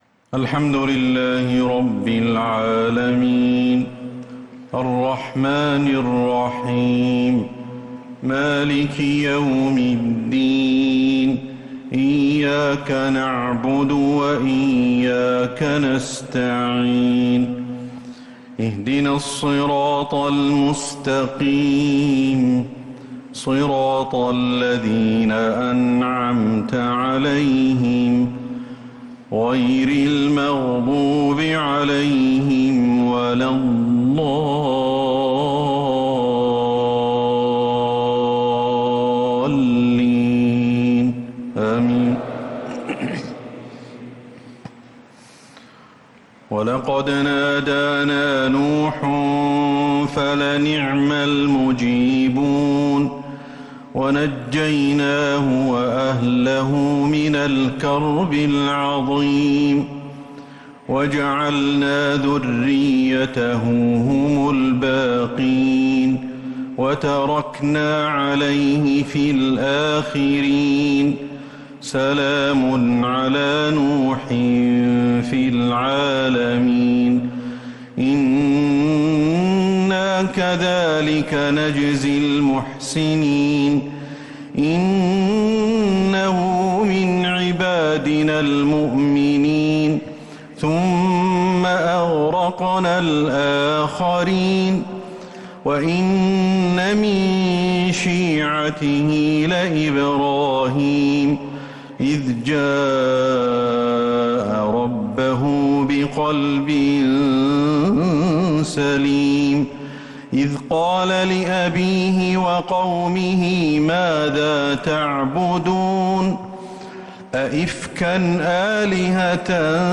صلاة العشاء للقارئ أحمد الحذيفي 12 صفر 1446 هـ
تِلَاوَات الْحَرَمَيْن .